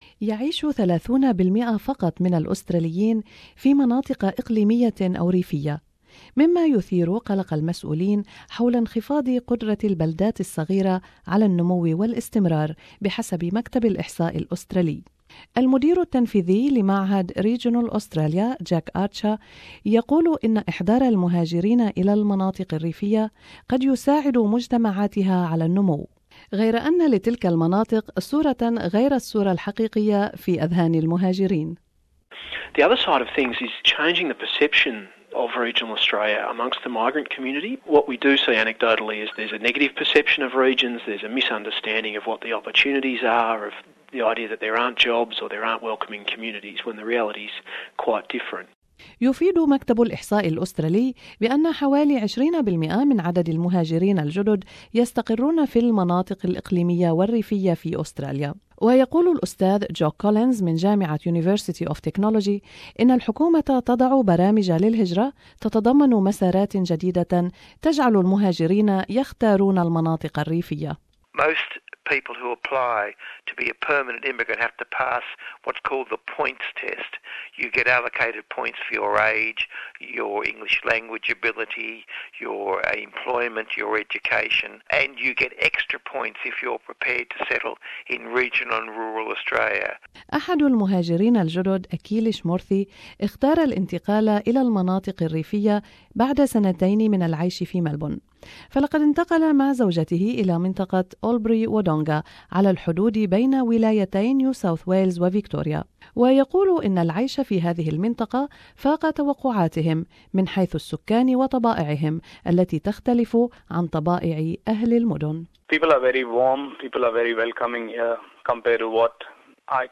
المزيد من التفاصيل في هذا التقرير